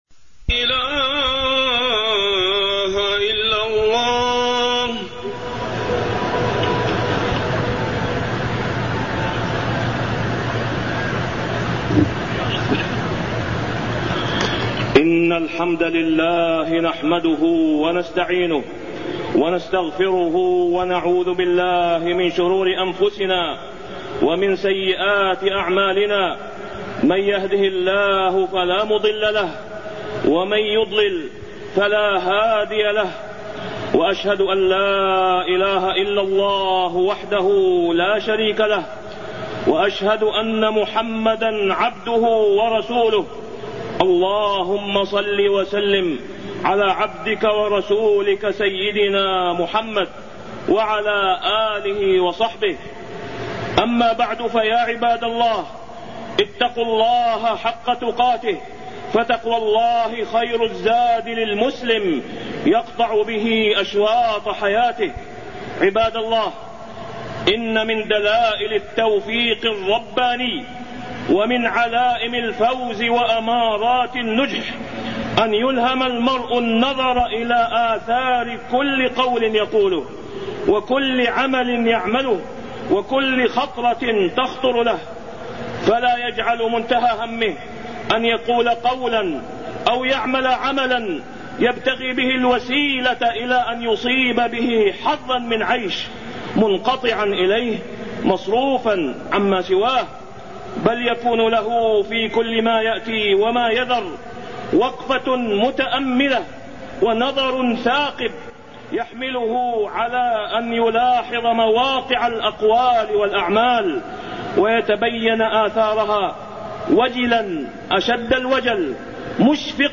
تاريخ النشر ٤ جمادى الأولى ١٤٢١ هـ المكان: المسجد الحرام الشيخ: فضيلة الشيخ د. أسامة بن عبدالله خياط فضيلة الشيخ د. أسامة بن عبدالله خياط ملة إبراهيم عليه السلام